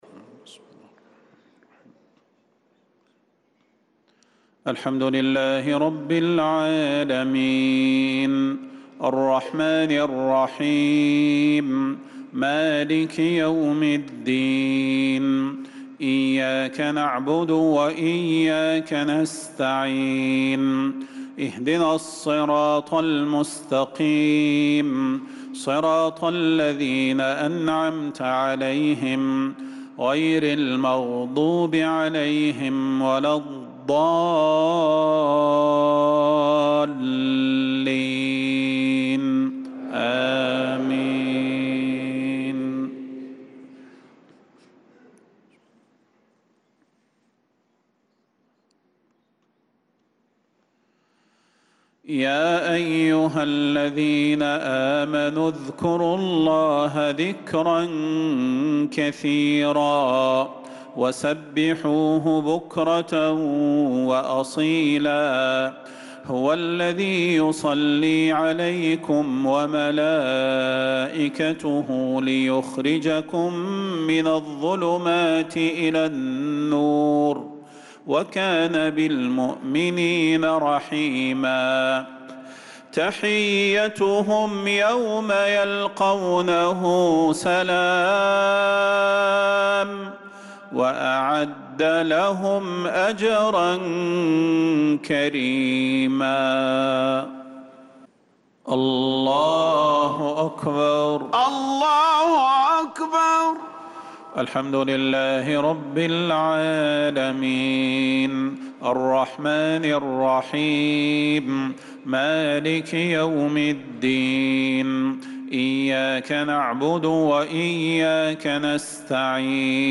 صلاة العشاء للقارئ صلاح البدير 12 شوال 1445 هـ
تِلَاوَات الْحَرَمَيْن .